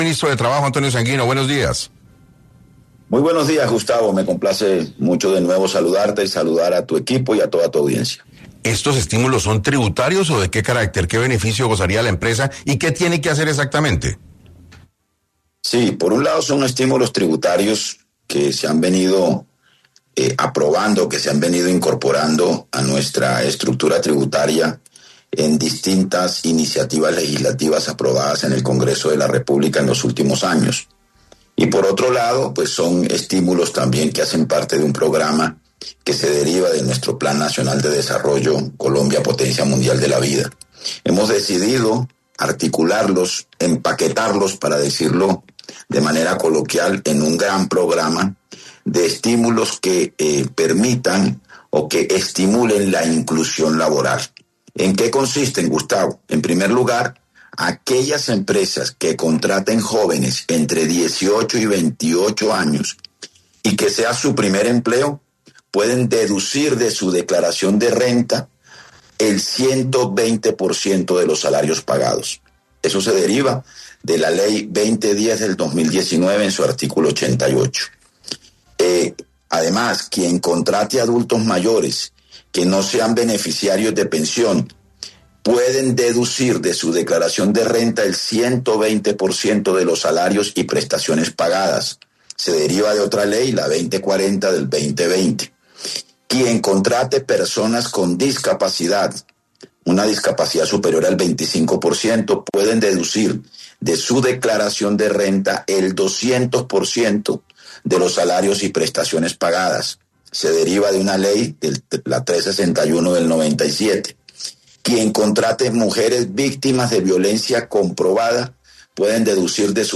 En entrevista con 6AM de Caracol Radio, el ministro del Trabajo Antonio Sanguino, habló de esta iniciativa y contó que son estímulos tributarios que se han venido aprobando e incorporando a la estructura tributaria en distintas iniciativas legislativas aprobadas en el Congreso de la República, además hacen parte de un programa que se deriva del Plan Nacional de Desarrollo Colombia Potencia Mundial de la Vida.